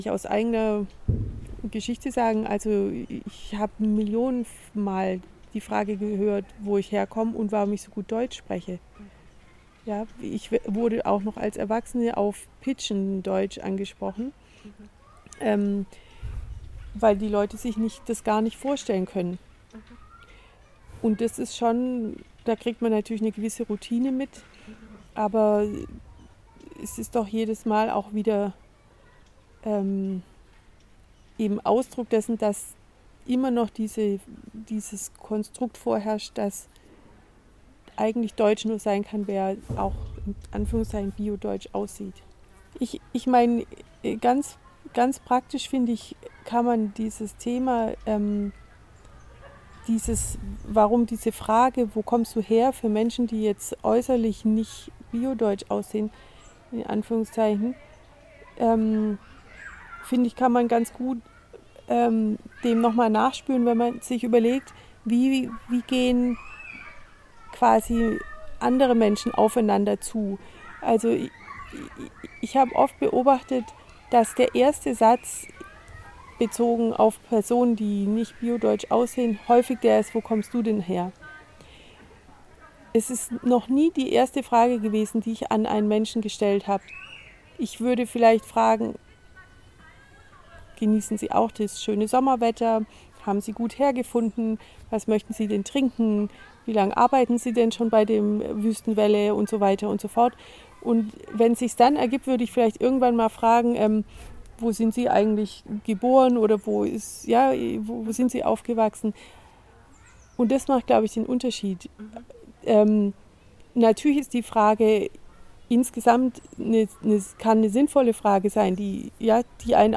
In ihrem Garten sprachen wir in diesem Sommer über ihren Werdegang